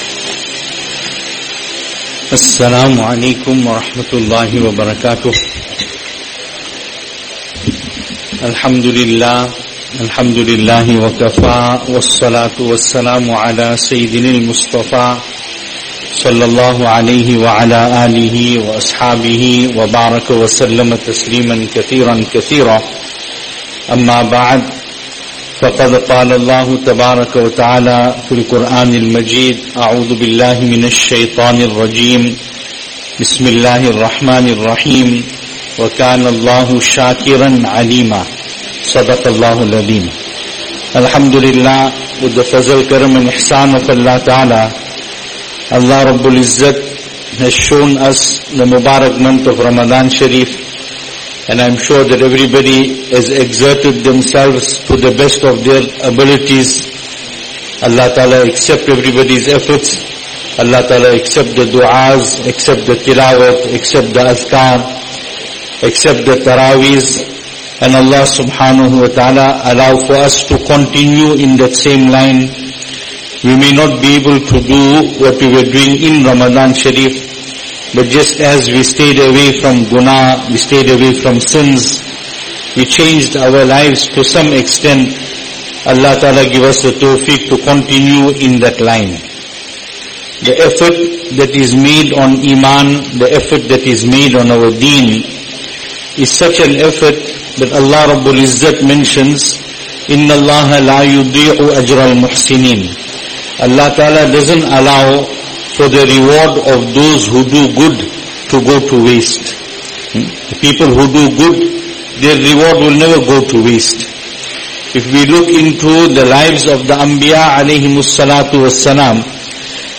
Jumuah Bayaan